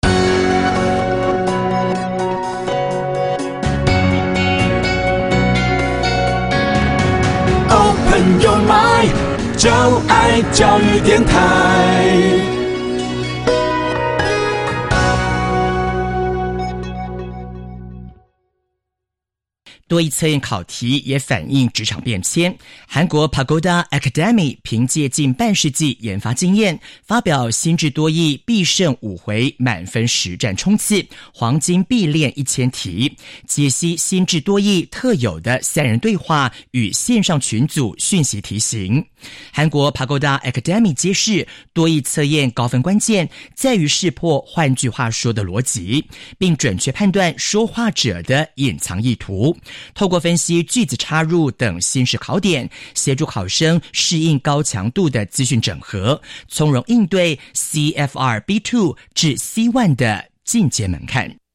6. 專業外師錄製聽力內容，熟悉多國口音特色
針對多益測驗常見多國口音，由多位專業外師精心錄製聽力測驗內容，幫助考生熟悉各國腔調特色，上場應考不用怕！